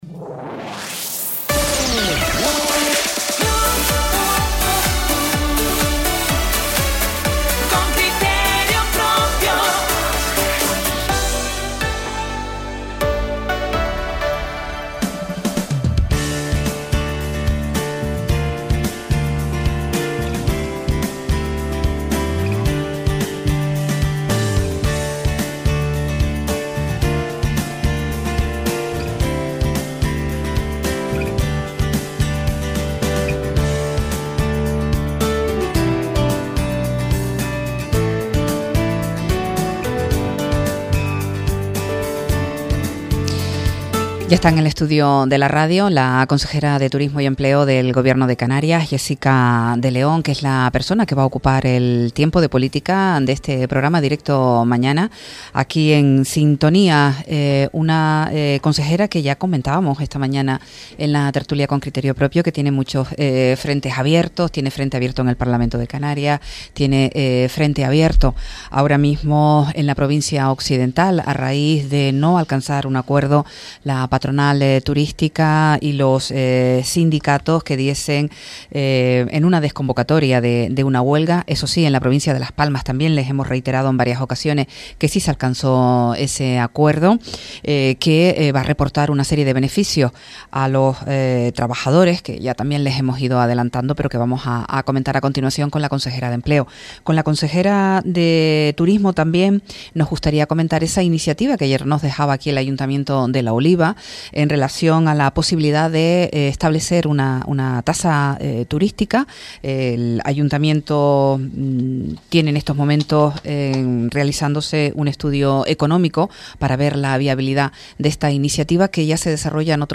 Jéssica de León consejera de Turismo y Empleo del Gobierno de Canarias intervino hoy en Radio Sintonía, en el programa de Directo mañana.
Entrevistas